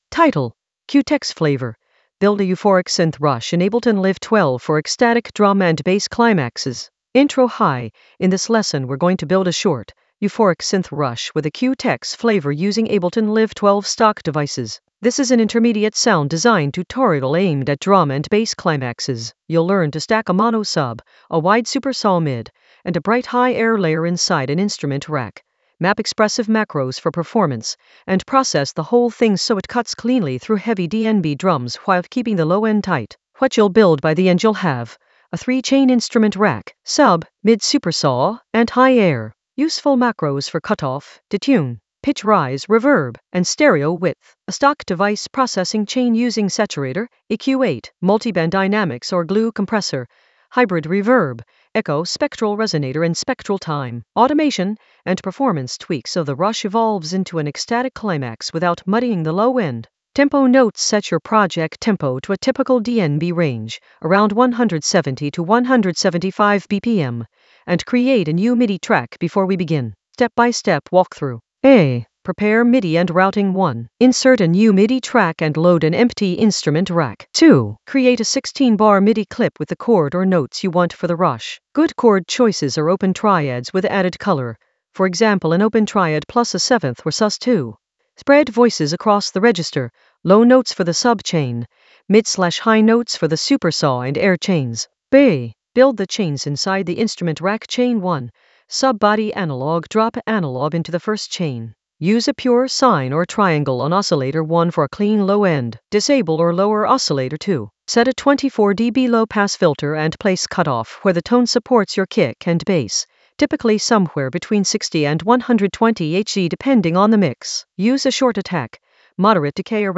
Narrated lesson audio
The voice track includes the tutorial plus extra teacher commentary.
An AI-generated intermediate Ableton lesson focused on Q-Tex flavour: build a euphoric synth rush in Ableton Live 12 for ecstatic drum and bass climaxes in the Sound Design area of drum and bass production.